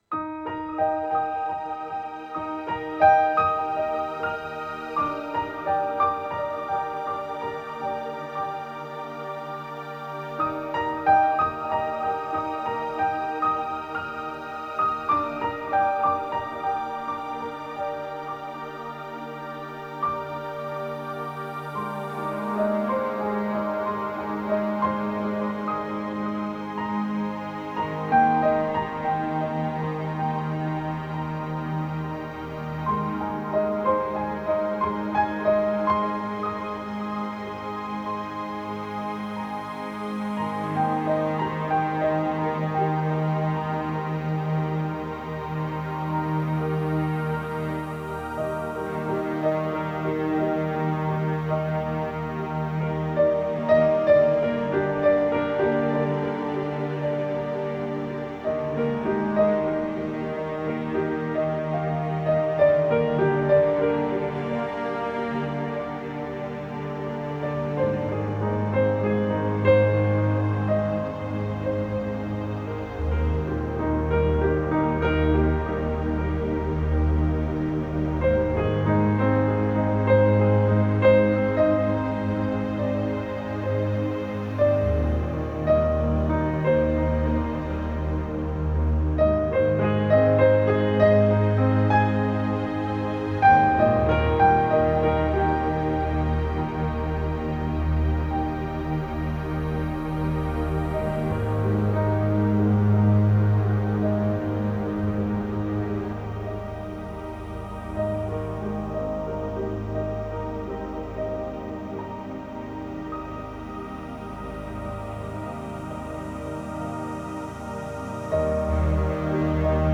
meditative piano albums